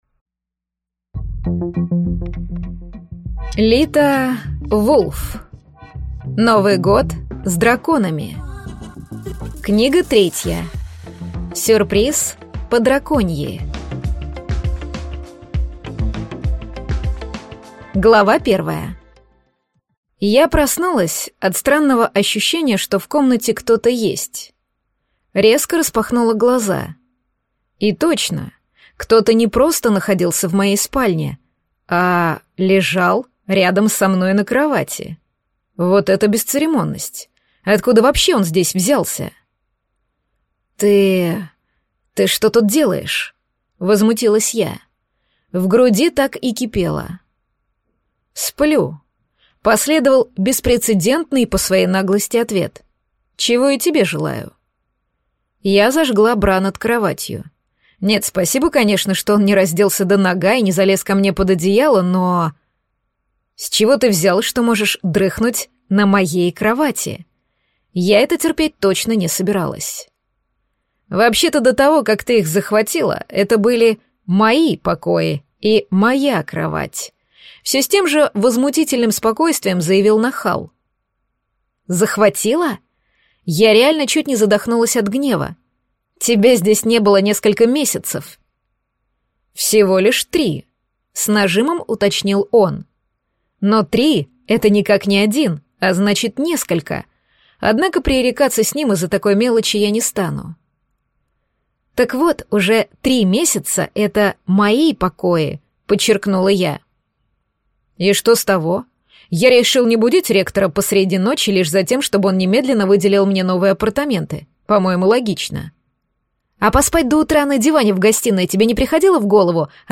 Аудиокнига Сюрприз по-драконьи | Библиотека аудиокниг